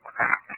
Common EVP Phrases
Are Phrases We Often Hear When Recording EVP